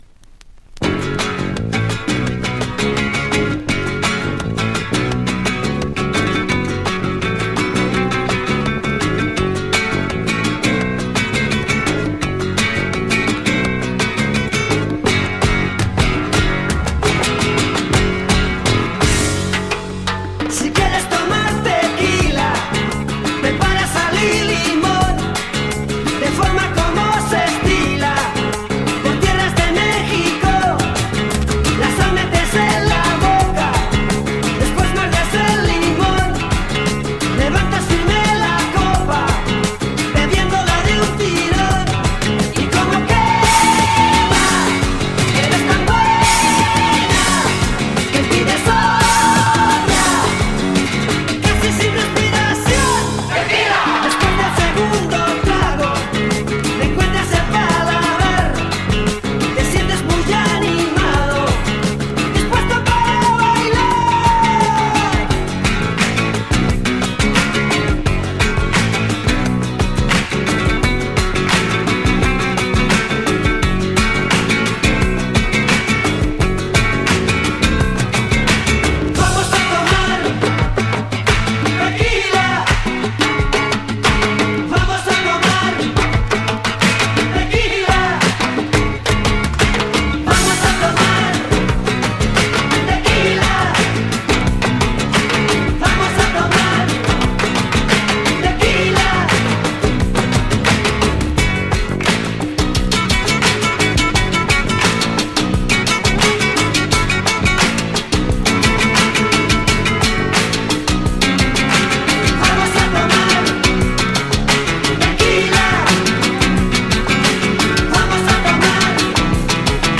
World / Other spain
スペインの最強ルンバロック・デュオ
粘っこいグルーヴを放つリズムに「テキーラ！」の大合唱も迫力満点な